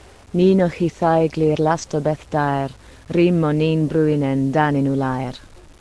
reciting this rhyme.